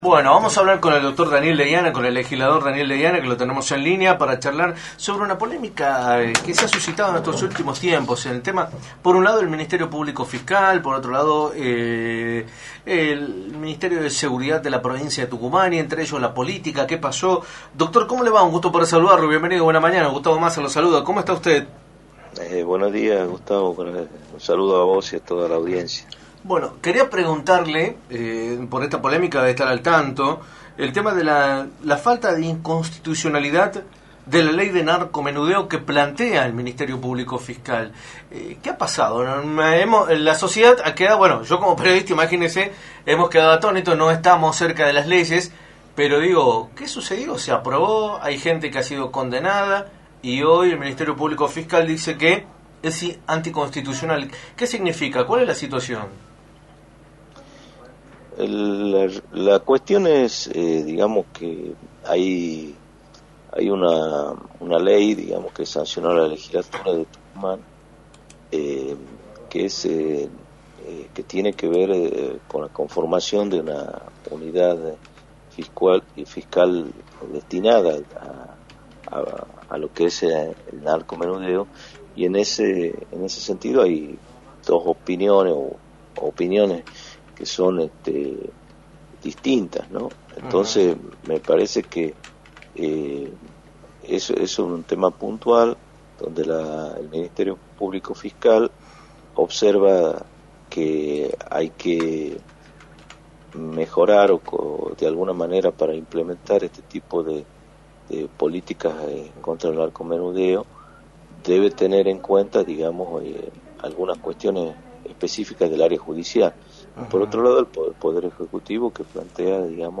El Dr. Daniel Deiana, Legislador del oficialismo provincial, analizó en Radio del Plata Tucumán, por la 93.9, el escenario político provincial, y abordó la polémica ocasionada a raíz de la declaración del Ministerio Público Fiscal, al asegurar que la Ley de Narcomenudeo es inconstitucional.